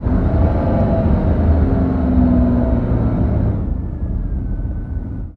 decelerate.ogg